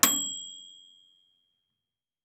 MACHAppl_Microwave bell (ID 1631)_BSB.wav